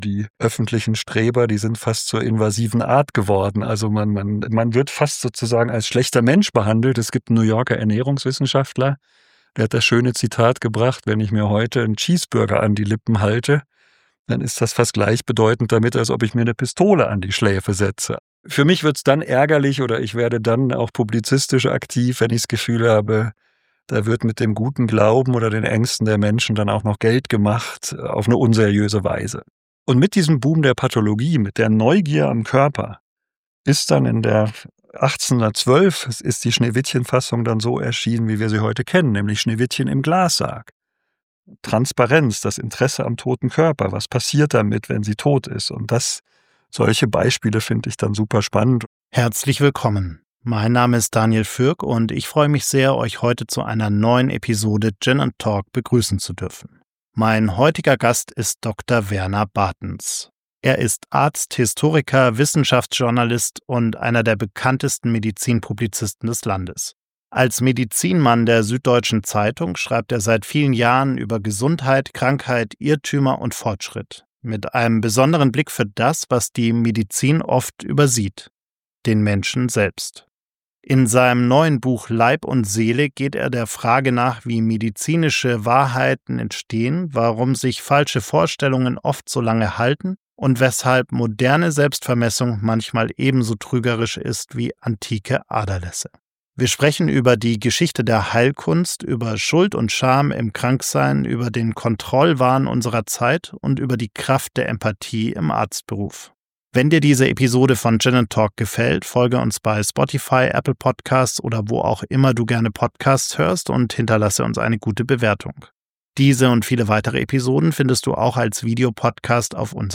Zwei Menschen, eiskalte, perfekt gemixte Drinks und eine Menge Zeit für inspirierende Bargespräche: Mach es Dir an unserem Tresen gemütlich und lerne interessante Menschen mit unterschiedlichsten Passionen, Professionen und Geschichten kennen!